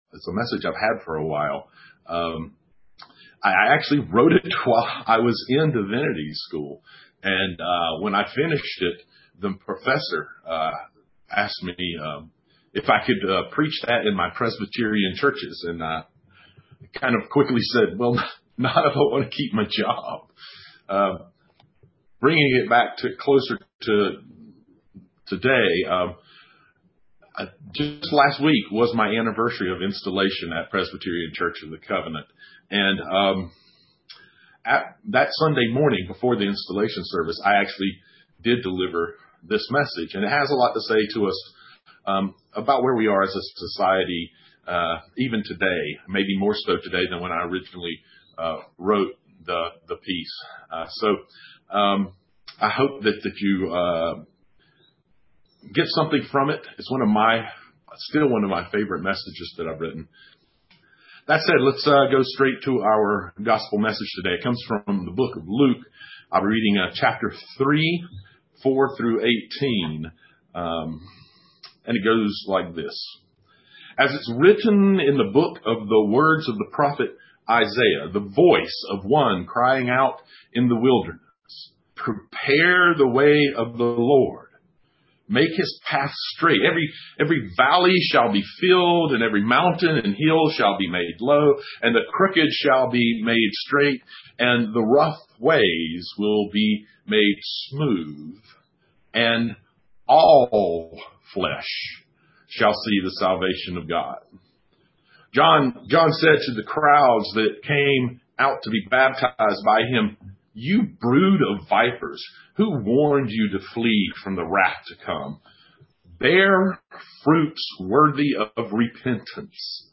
(streamed via Facebook and Zoom)